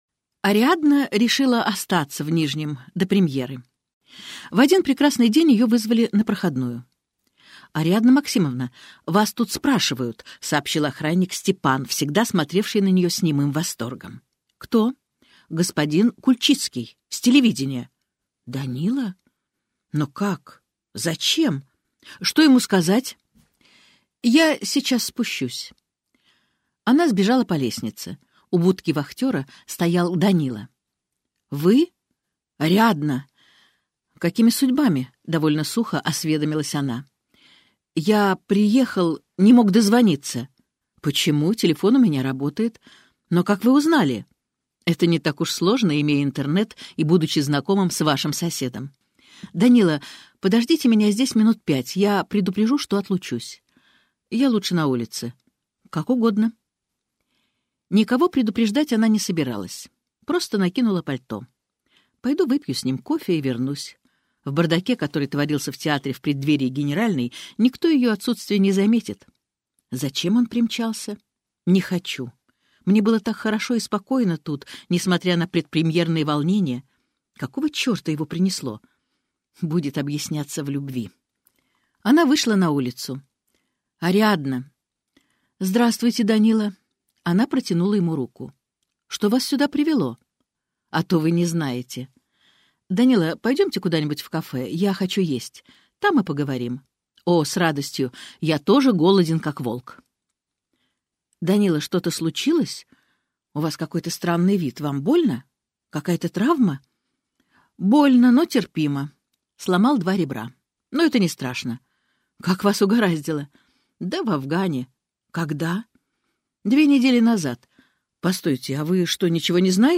Аудиокнига Фиг ли нам, красивым дамам! - купить, скачать и слушать онлайн | КнигоПоиск